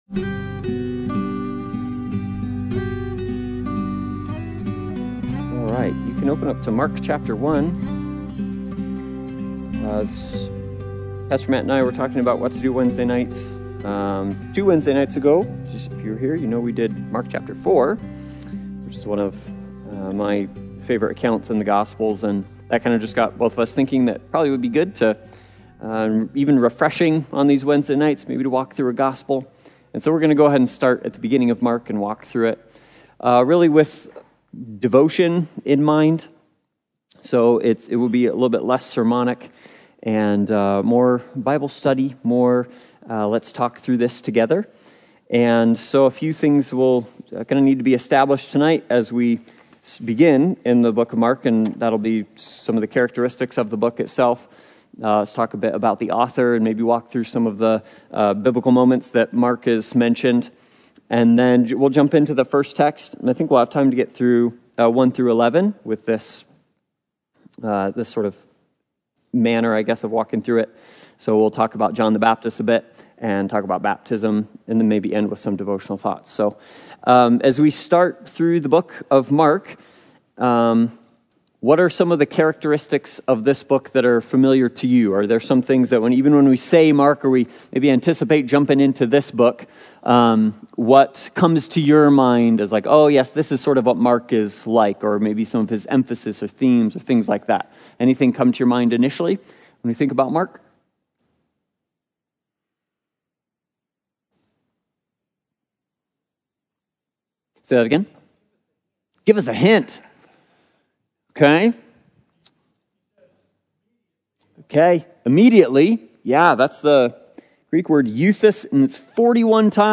The Gospel According to Mark Service Type: Sunday Bible Study « Walk Rightly